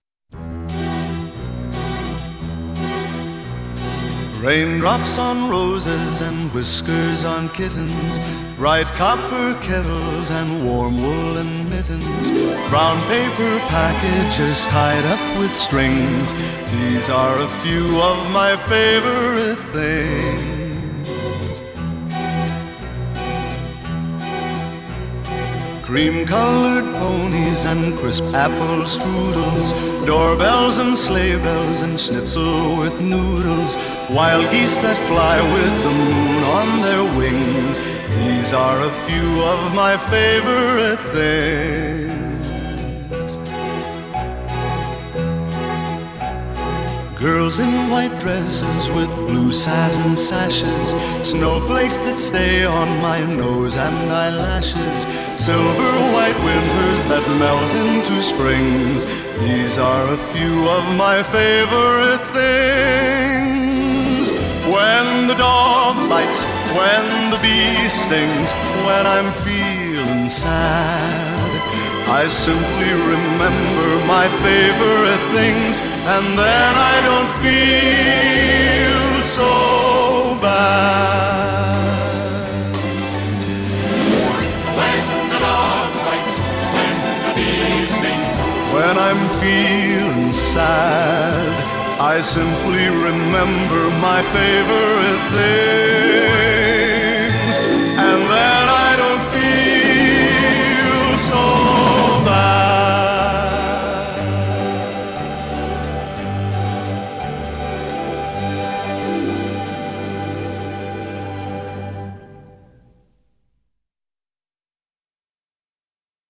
02 Viennese Waltz